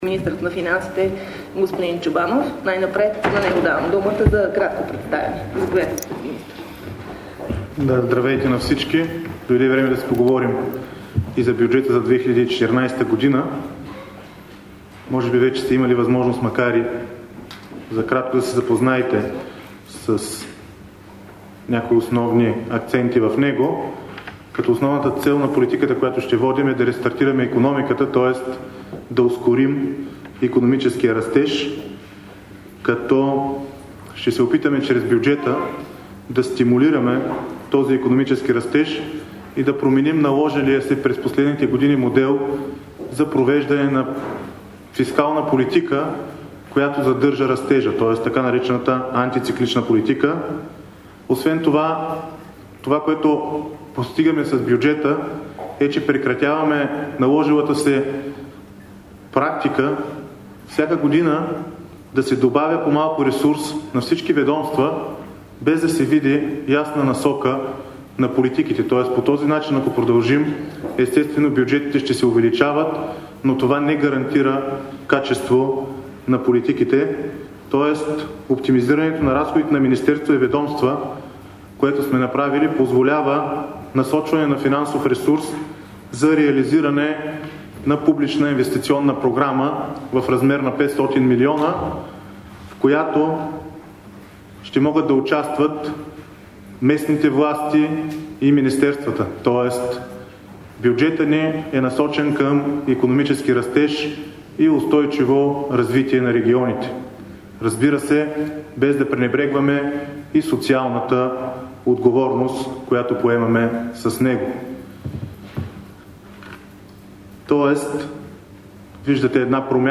Представяне на бюджет 2014 г. от финансовия министър Петър Чобанов на пресконференция в Министерския съвет